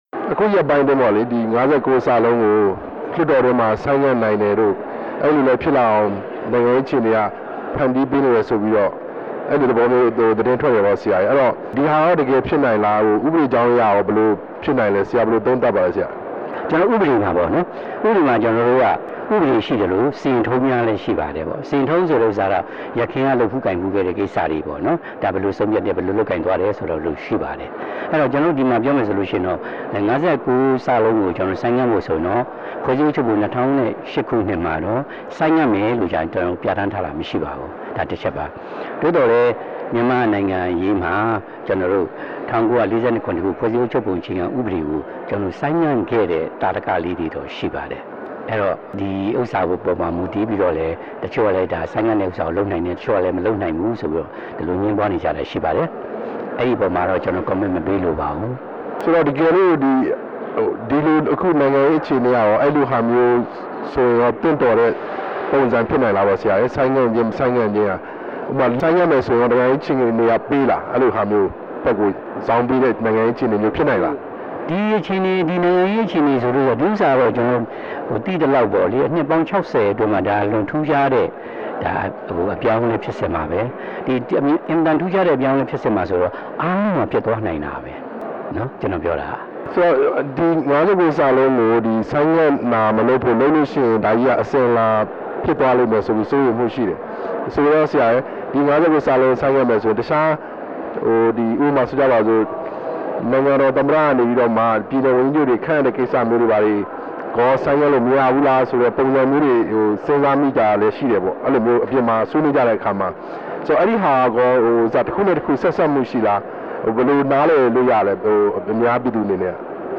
NLD ဦးထွန်းထွန်းဟိန်နဲ့ မေးမြန်းချက်